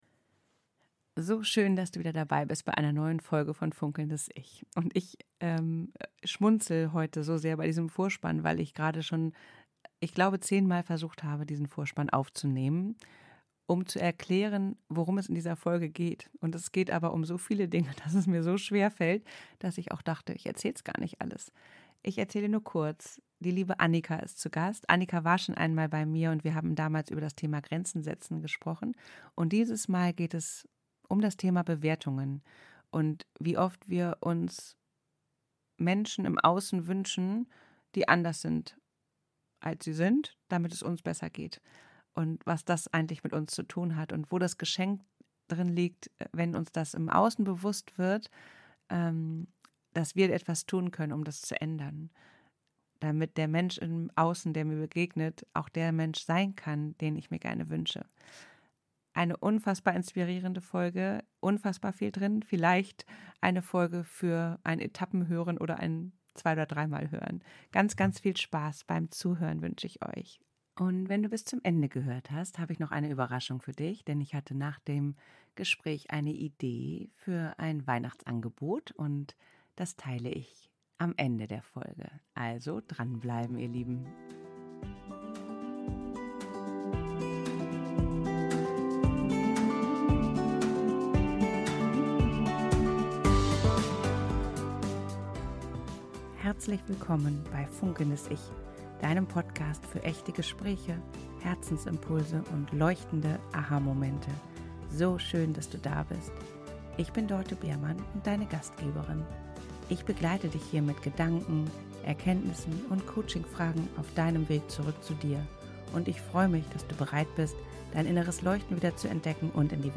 Ein ehrliches, lebendiges Gespräch über Bewertungen, alte Muster, Mut, Selbstverantwortung – und die heilsame Möglichkeit, Beziehung völlig neu zu erleben, wenn wir aufhören zu warten, dass der andere sich ändert.